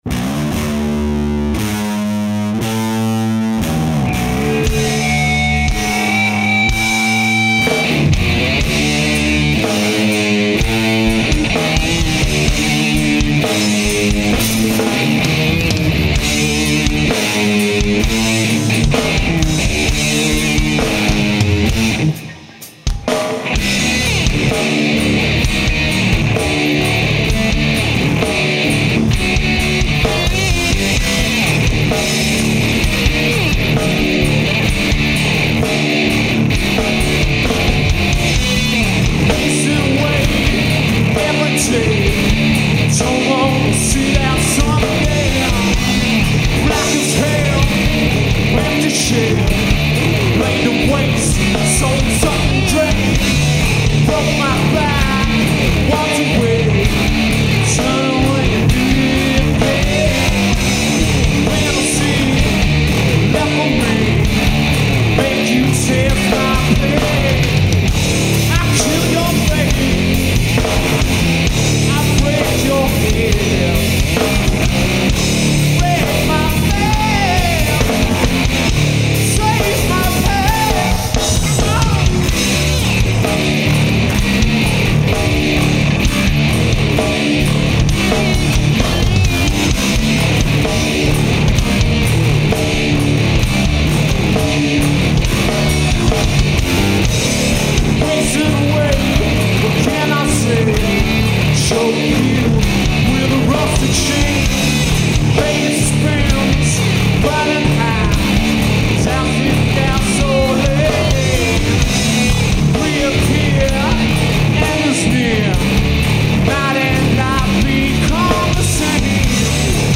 Live from the Hard Club, Oporto